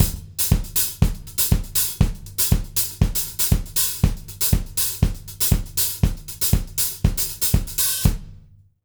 120ZOUK 06-L.wav